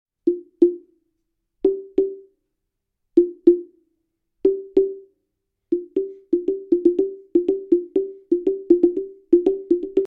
اسلایت درام بامبو دو نت TERRE| فروشگاه سرنا
TERRE Slit Drum bamboo 2 tones | فروشگاه سرنا | اسلایت درام بامبو دو نت TERRE